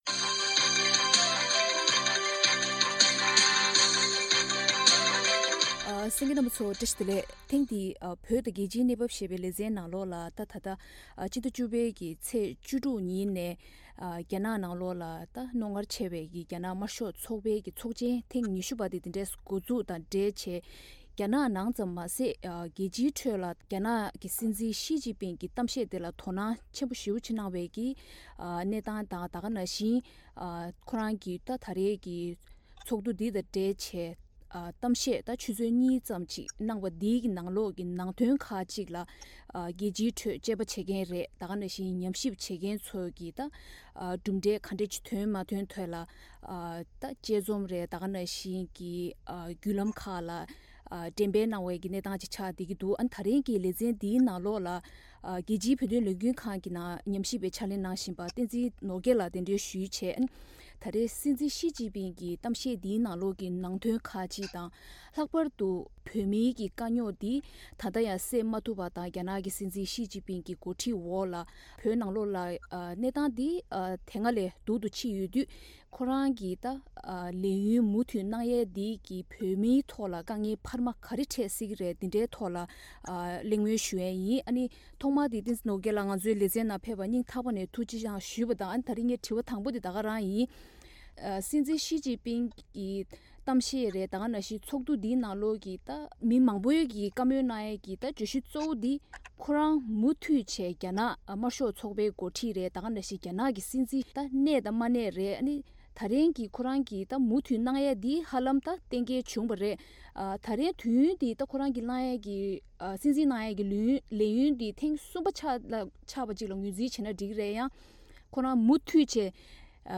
གླེང་མོལ་གནང་བར་གསན་རོགས་ཞུ།།